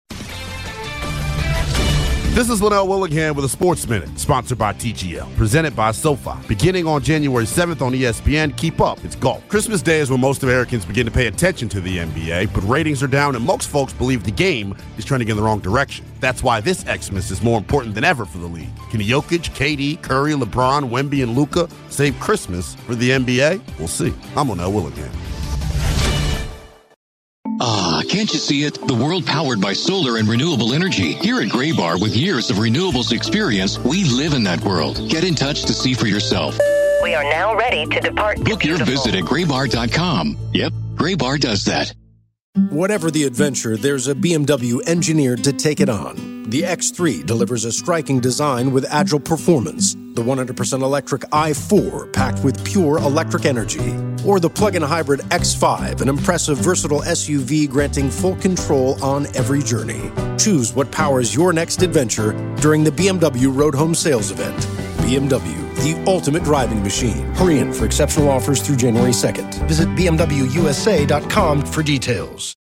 Hourly Commentaries between 6am-7pm by Infinity Sports Network talent